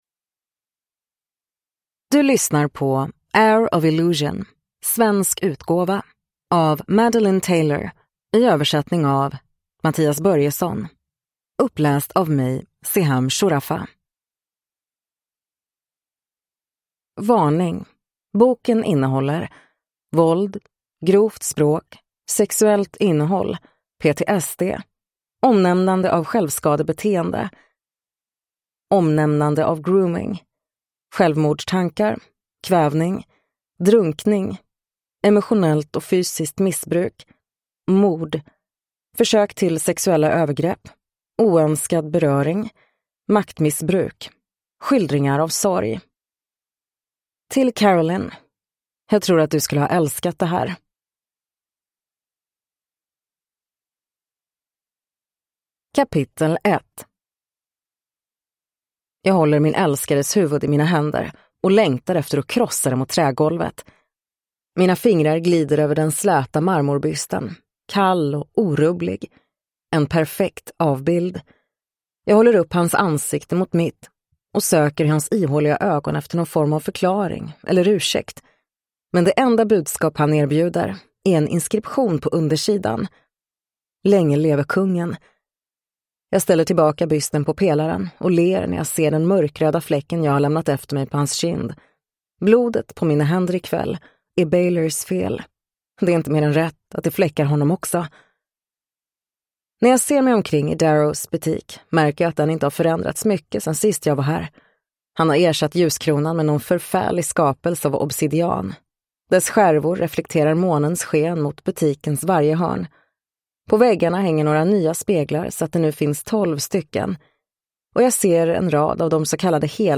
Heir of Illusion – Ljudbok